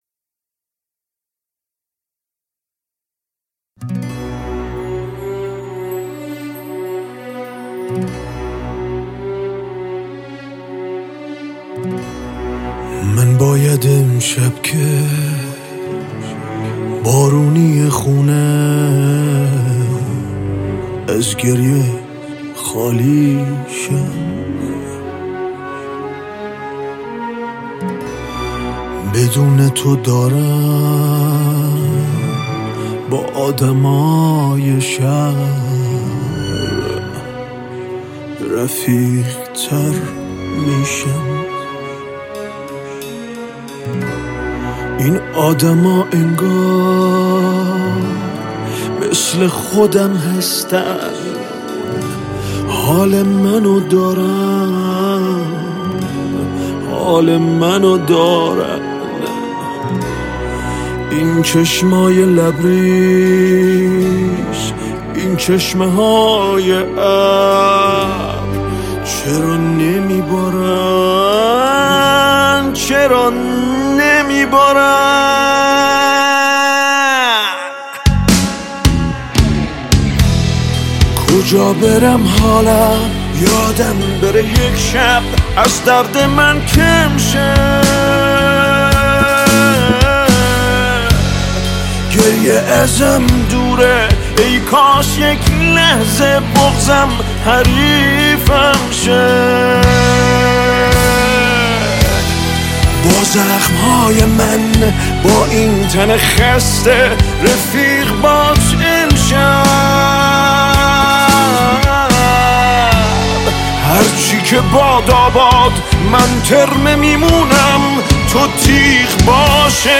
آهنگ تیتراژ فیلم سینمایی